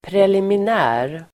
Uttal: [prelimin'ä:r]